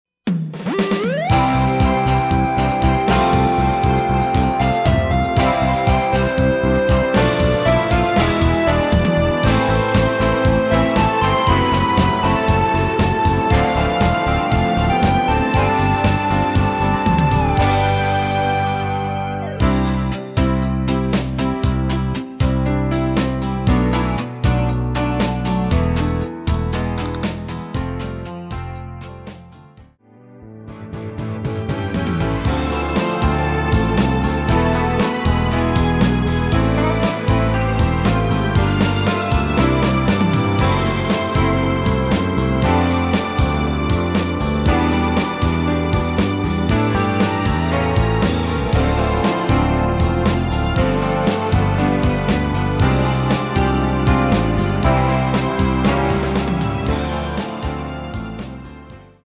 내린 MR 입니다.